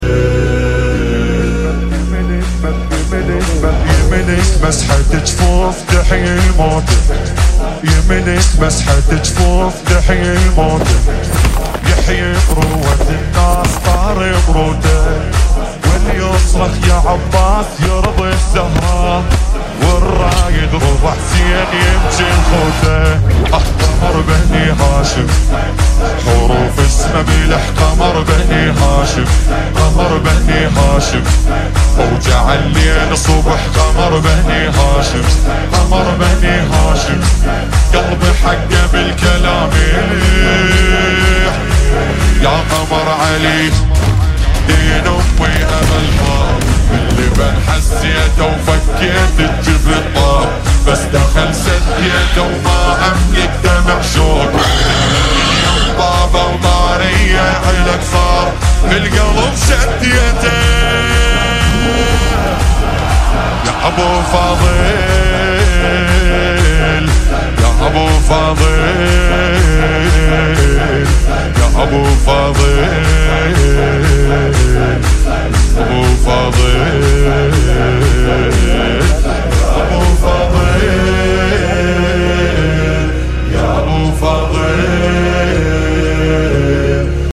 Almost all rare emotes pubg sound effects free download